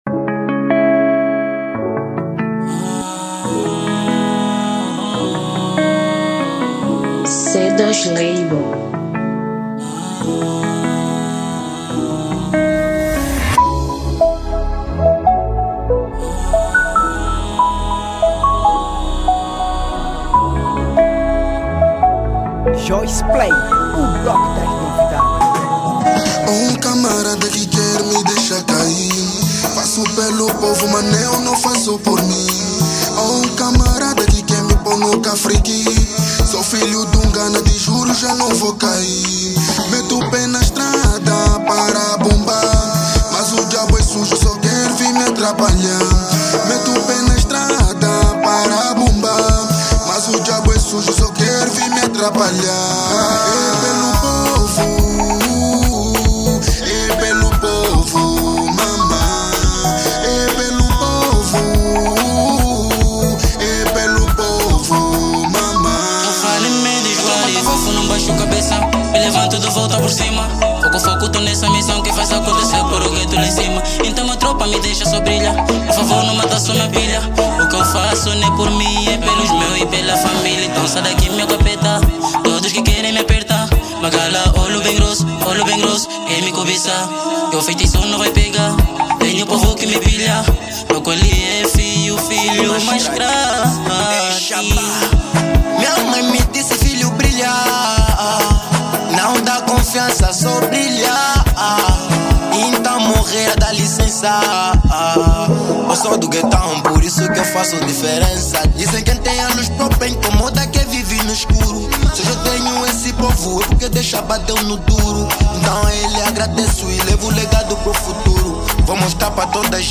| Afro house